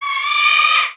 scream.wav